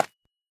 Minecraft Version Minecraft Version 1.21.5 Latest Release | Latest Snapshot 1.21.5 / assets / minecraft / sounds / block / calcite / place4.ogg Compare With Compare With Latest Release | Latest Snapshot